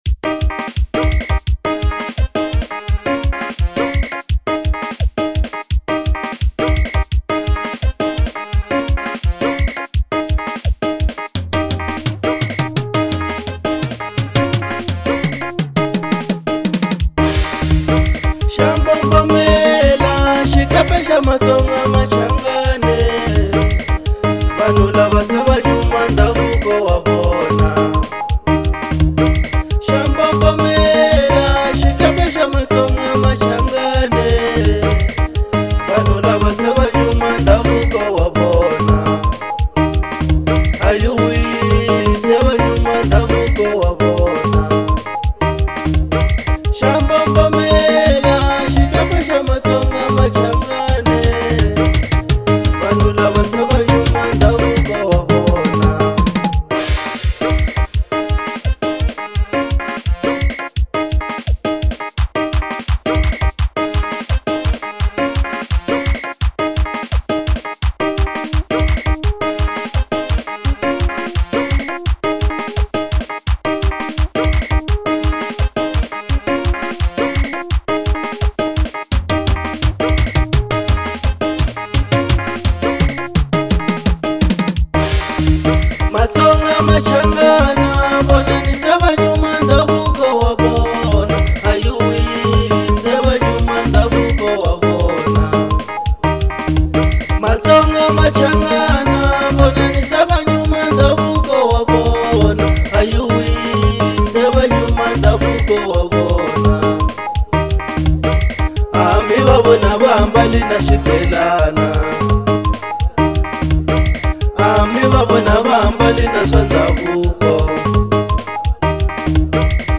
05:41 Genre : Xitsonga Size